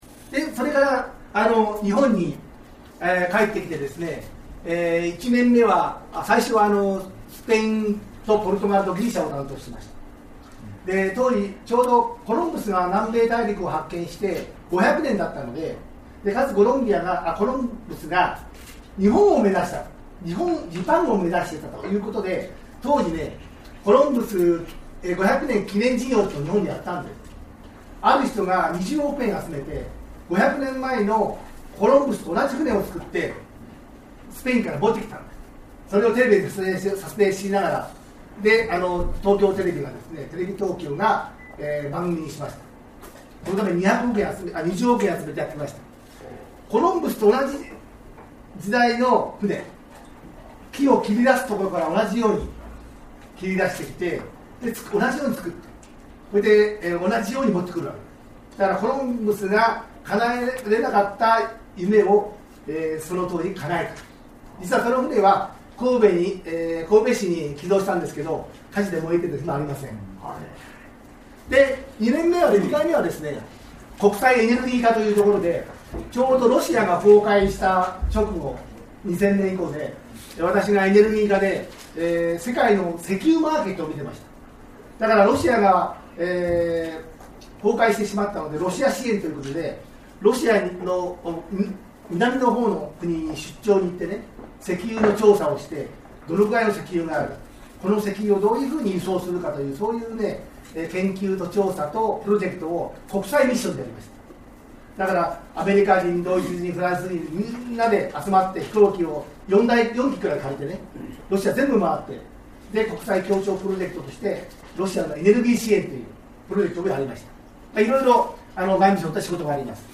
1月25日（金）竜峡中学校にて在ドミニカ共和国日本国大使館 日本国特命全権大使の牧内 博幸 氏による講演がありました。